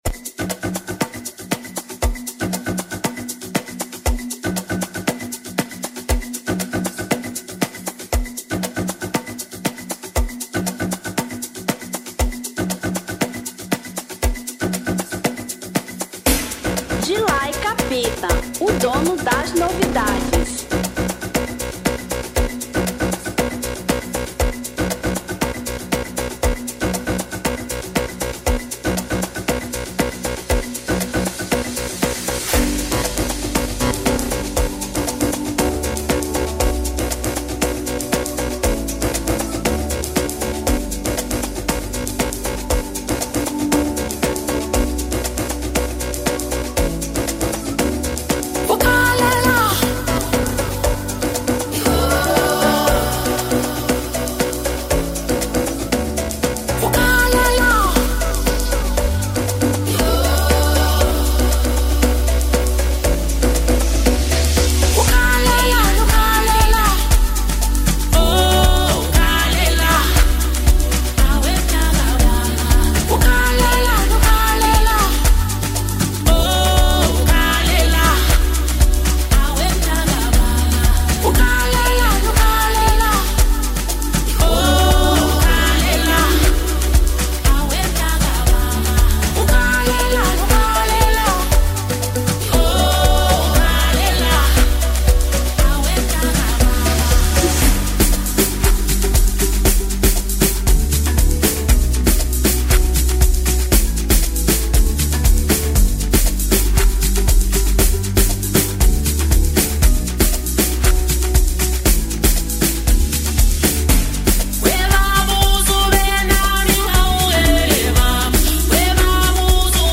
Gqom